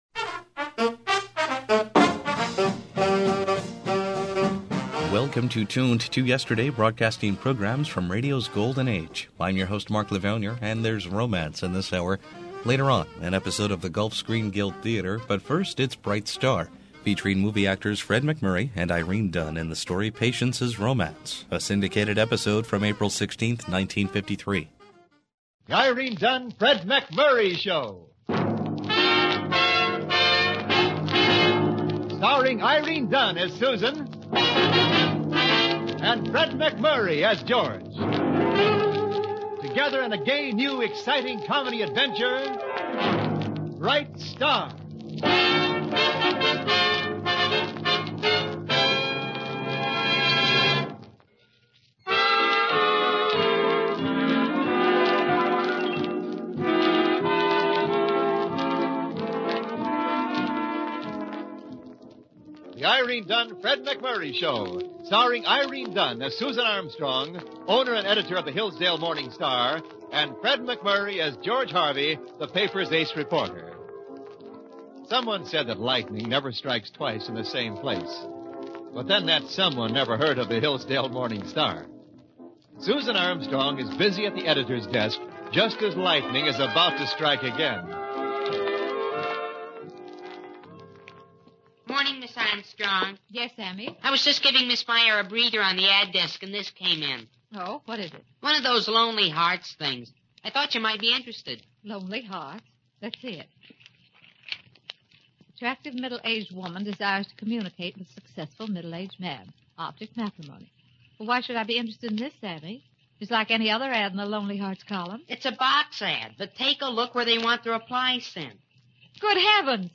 Decades ago, WRVO Public Media began broadcasting old-time radio with a small collection comprising 20 reel-to-reel tapes.
The highest quality broadcasts are restored and played as they were heard years and years ago.